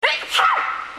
For all those wanting to hear one of my amazing sneezes. From Crowngate shopping centre, hence the echo.